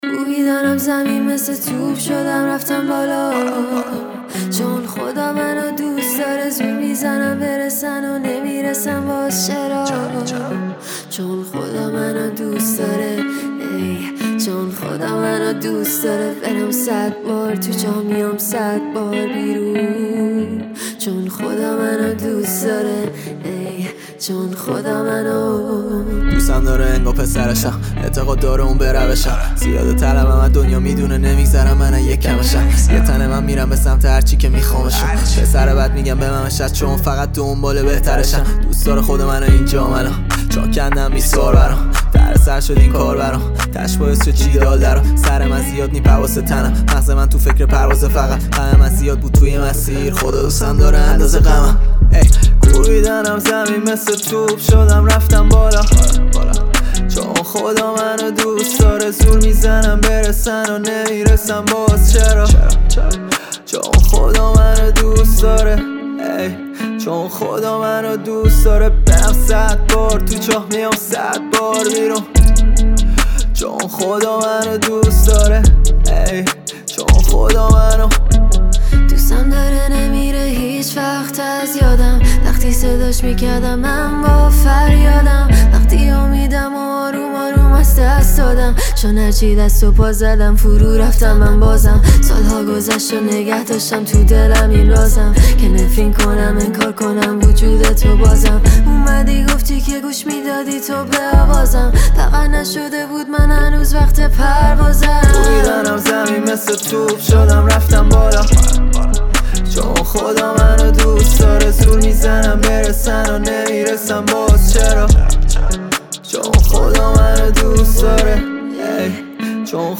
رپ
آهنگ با صدای زن